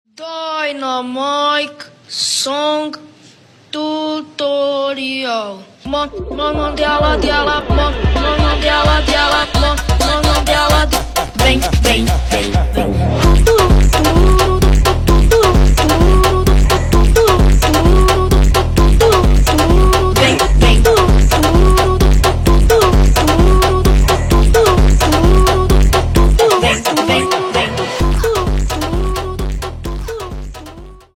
зарубежные клубные веселые